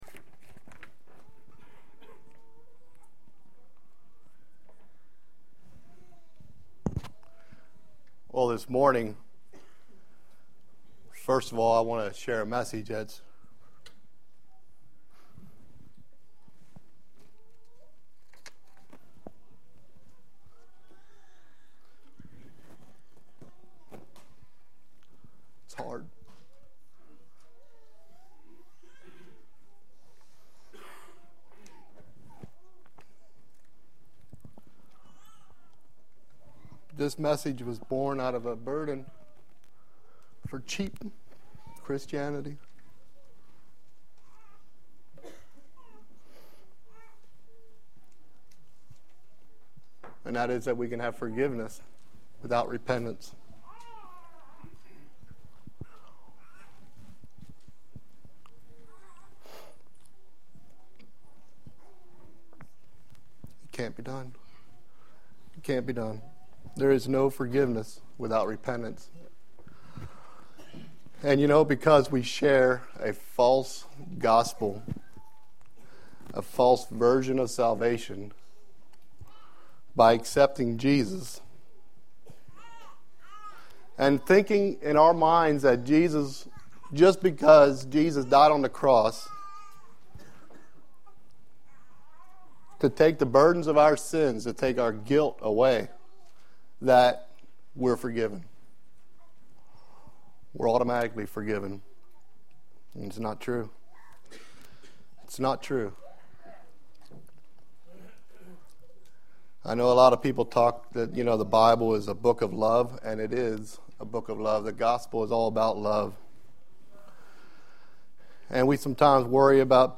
Sermons – Page 61 – Shiloh Mennonite